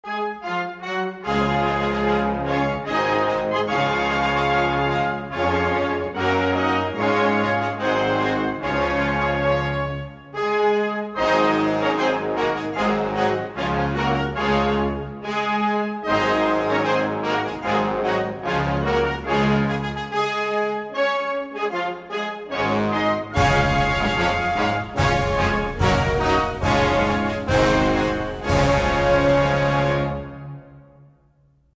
US_Navy_band_-_National_anthem_of_Estonia.mp3